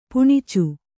♪ puṇicu